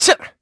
Riheet-Vox_Attack5_kr.wav